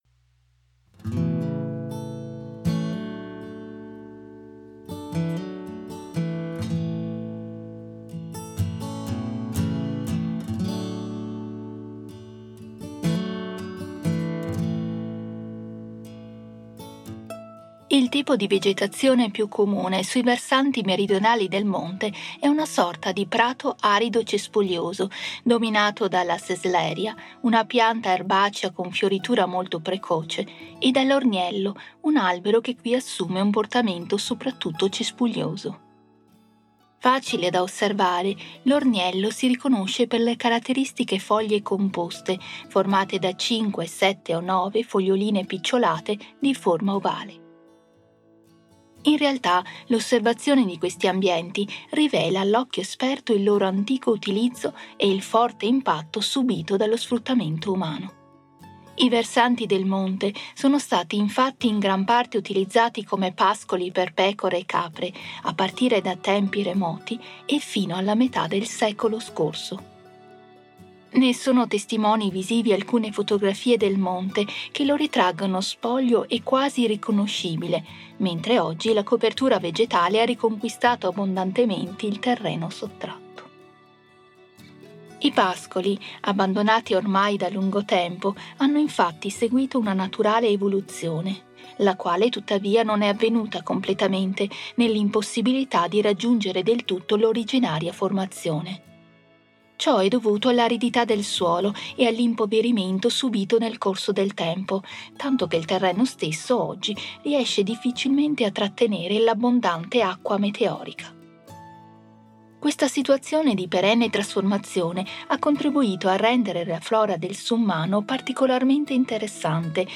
AUDIOGUIDA_Girolimini._05._Prati__alberti_e_boschi.mp3